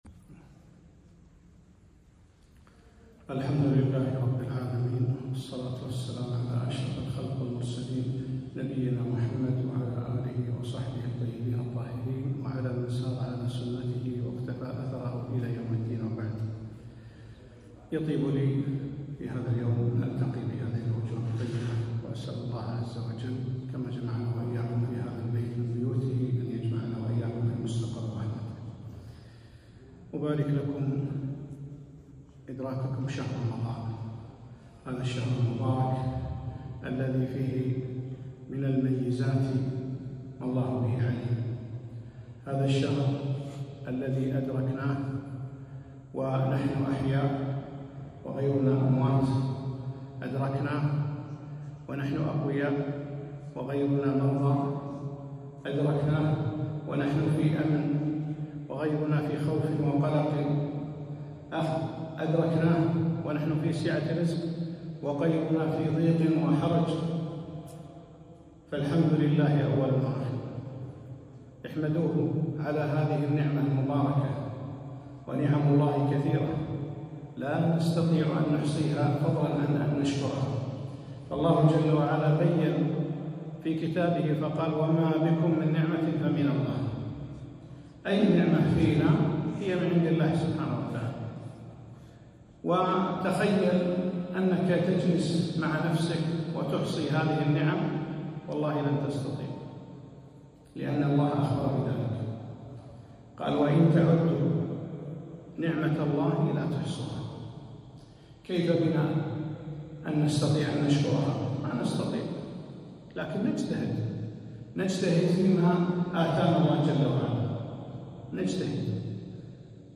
كلمة - حقيقة الصيام